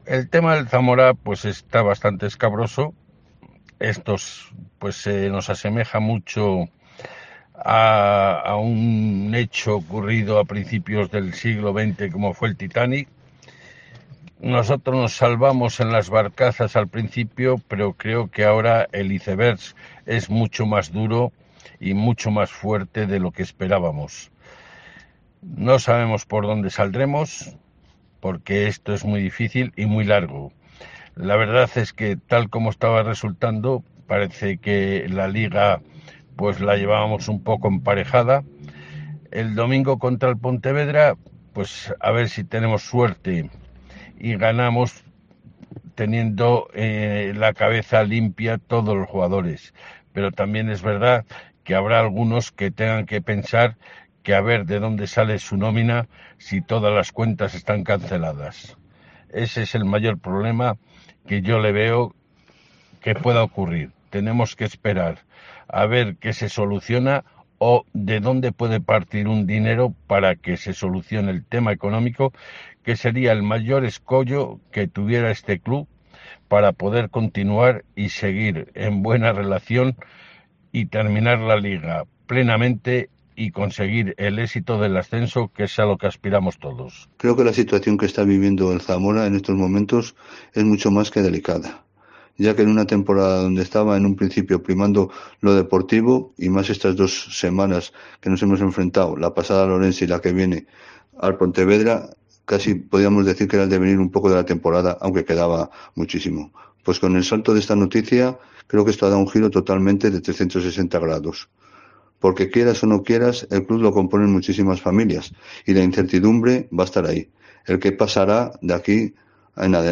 Aficionados del Zamora CF hablan sobre la detención de Víctor de Aldama